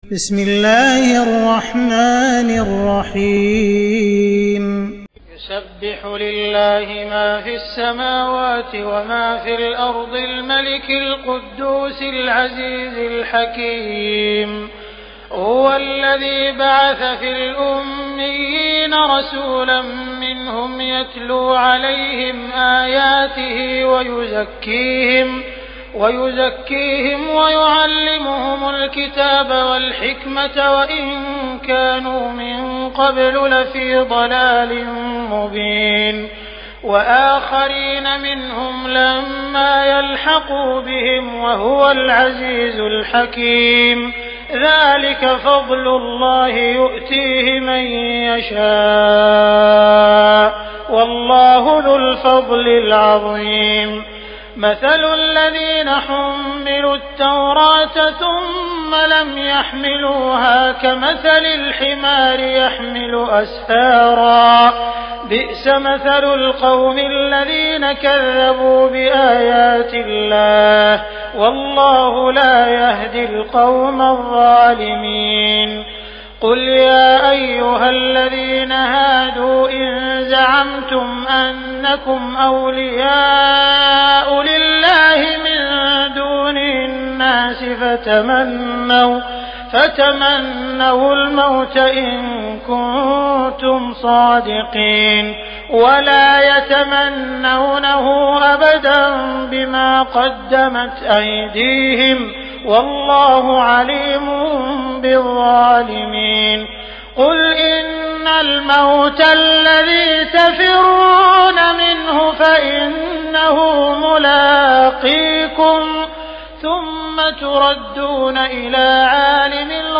تراويح ليلة 27 رمضان 1418هـ من سورة الجمعة الى التحريم Taraweeh 27 st night Ramadan 1418H from Surah Al-Jumu'a to At-Tahrim > تراويح الحرم المكي عام 1418 🕋 > التراويح - تلاوات الحرمين